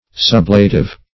Sublative \Sub"la*tive\, a.